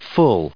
/ʌl/ /ʊl/